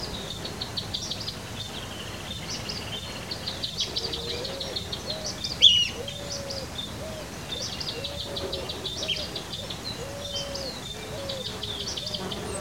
Bandurrita Chaqueña (Tarphonomus certhioides)
Nombre en inglés: Chaco Earthcreeper
Localización detallada: Laguna El Cristiano
Condición: Silvestre
Certeza: Vocalización Grabada